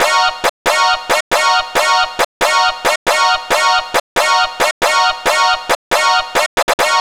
Lead 137-BPM D#.wav